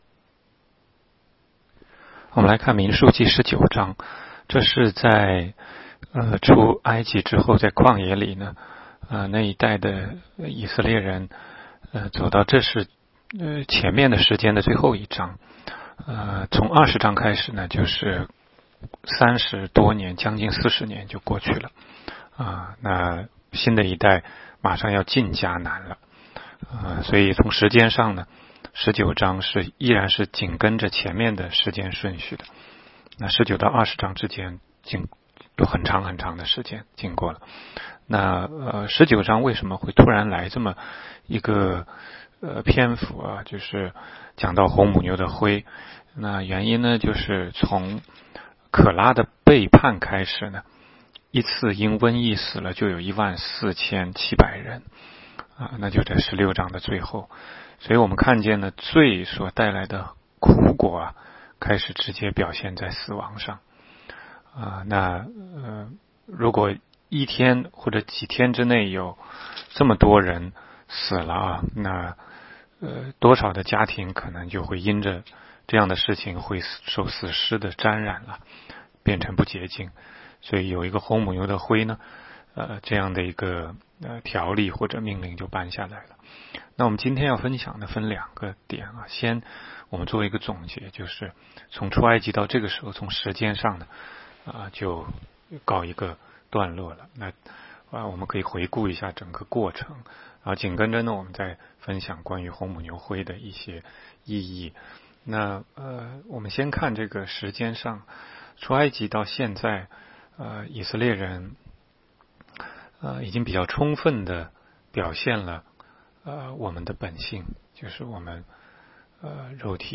16街讲道录音 - 每日读经-《民数记》19章